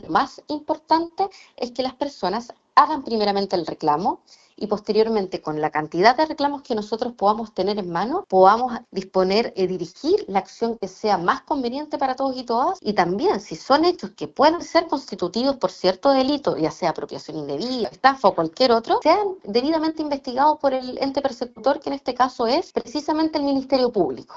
La directora regional de la institución, Fernanda Gajardo, recalcó la importancia de realizar el trámite para avanzar con el caso.